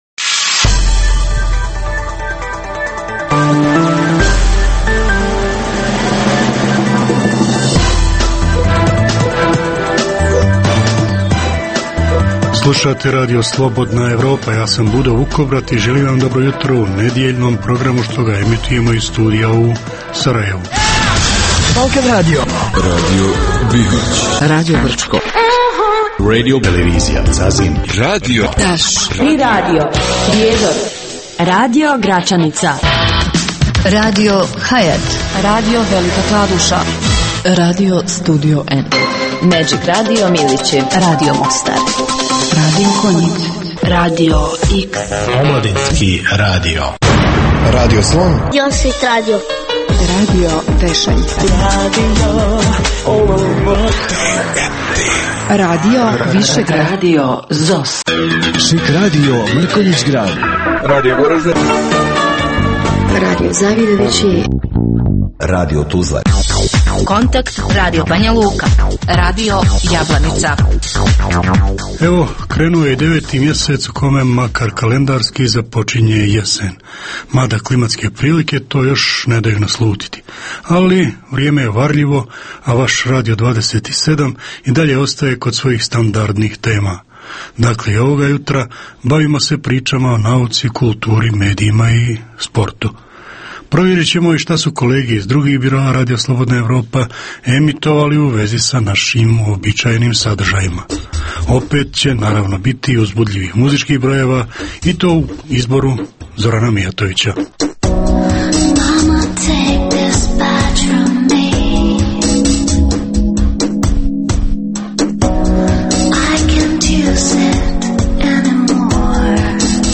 Jutarnji program namijenjen slušaocima u Bosni i Hercegovini. Uz vijesti i muziku, poslušajte pregled novosti iz nauke i tehnike, te čujte šta su nam pripremili novinari RSE iz regiona.